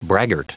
Prononciation du mot braggart en anglais (fichier audio)
Prononciation du mot : braggart